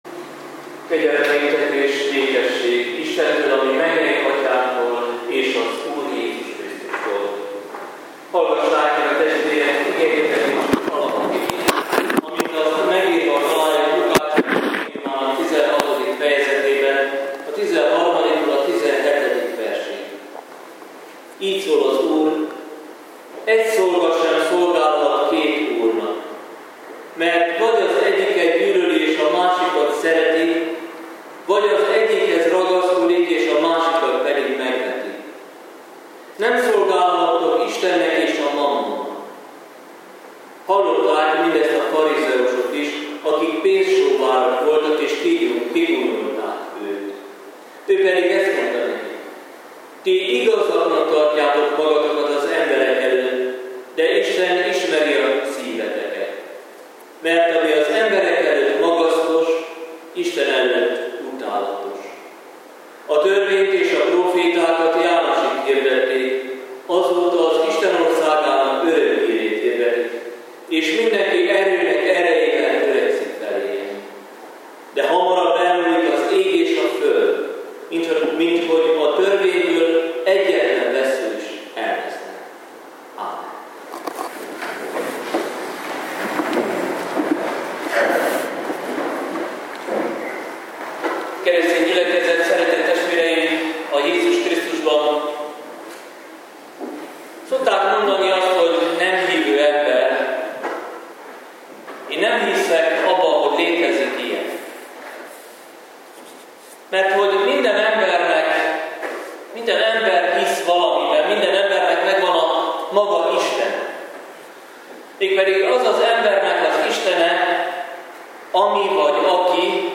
Szentháromság ünnepe után 12. vasárnap.